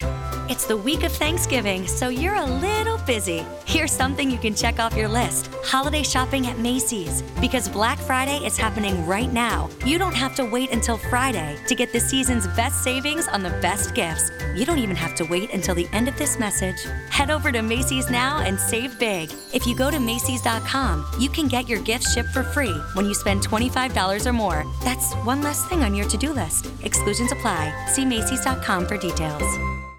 Here are some Black Friday audio ad samples that were heard across the world this year: